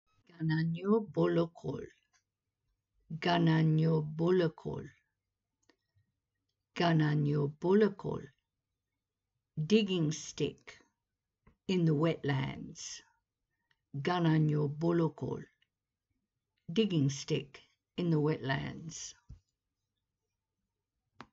About the name "Gananyu Bulukul" (Gan-an-yoo Bool-ook-ool)
Listen to how "Gananyu Bulukul" is pronounced